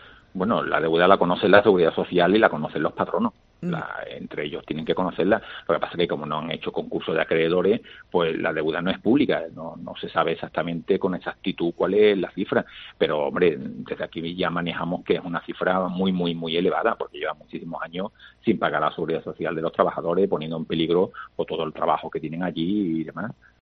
Manuel Antonio Conde, delegado de Igualdad, Políticas Sociales y Conciliación